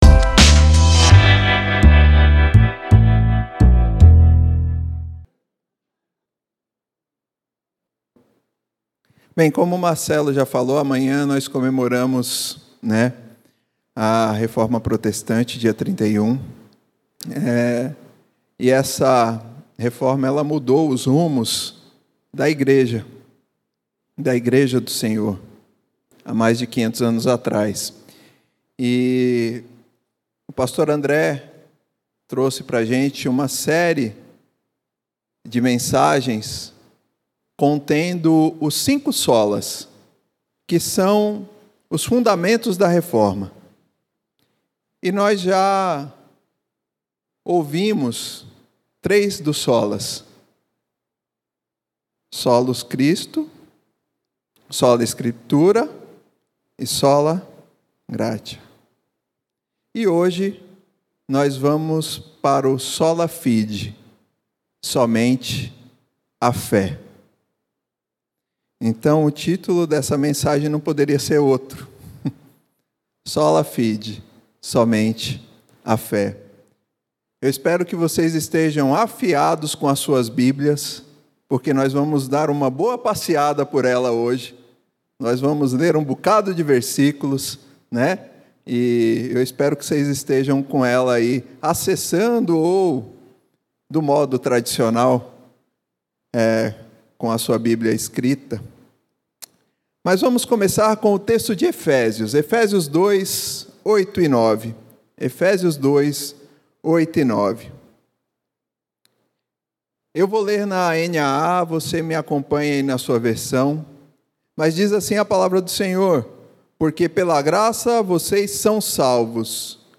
Segunda mensagem da série FÉ REFORMADA